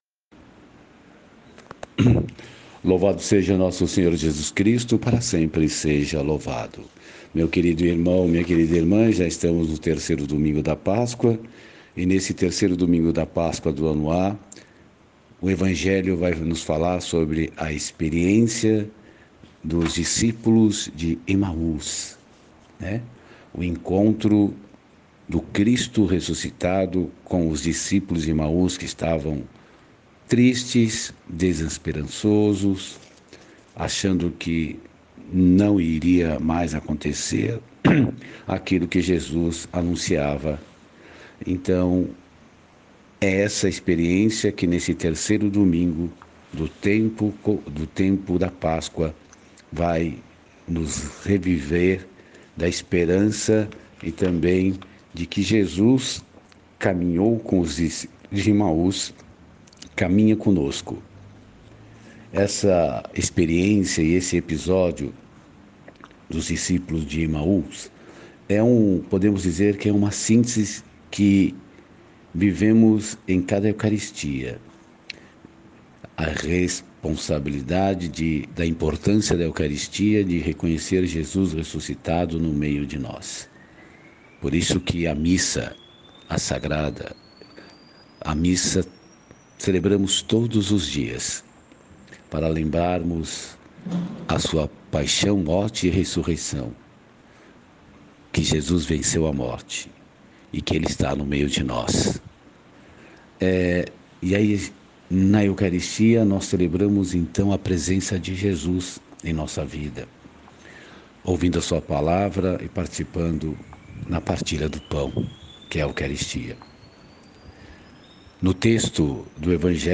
Reflexão e Meditação do 3 Domingo da Páscoa.